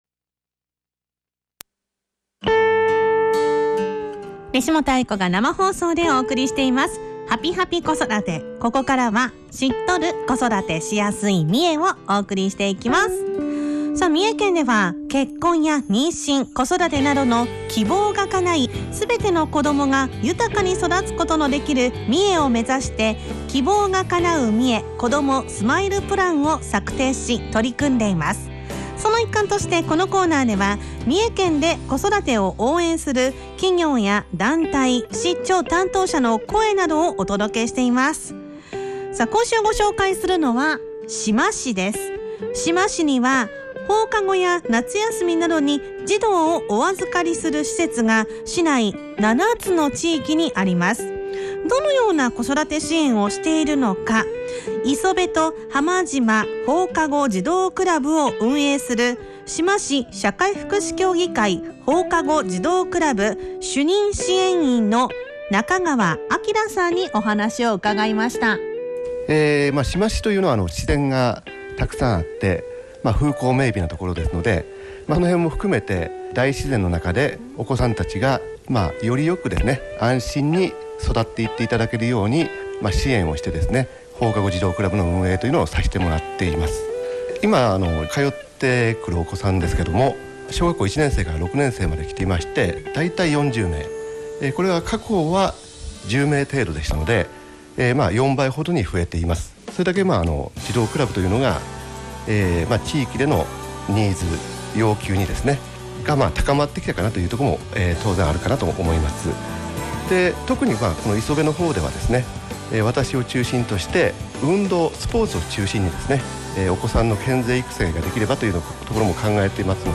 インタビュー
県内各市町の子育てを応援している団体などを取材し、地域で活躍されている方の生の声をFM三重「はぴぱぴ子育て」「EVENING COASTER」内で放送しました。